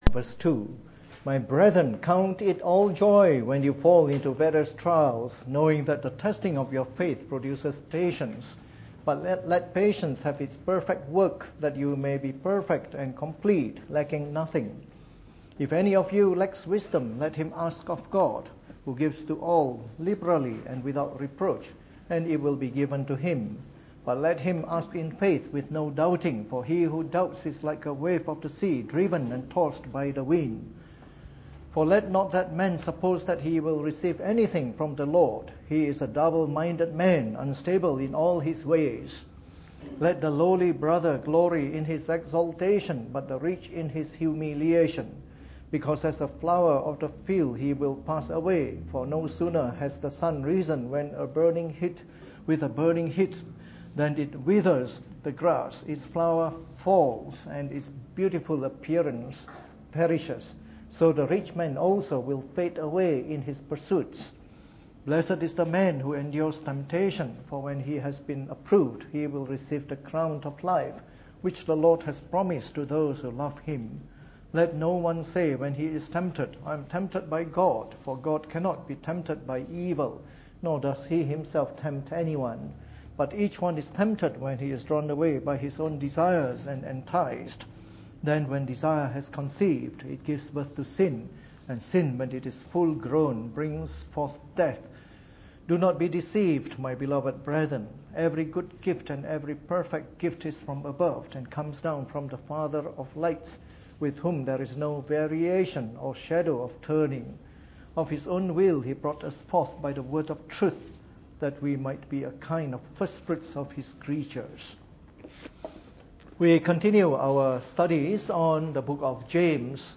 Preached on the 30th of September 2015 during the Bible Study, from our new series on the Epistle of James.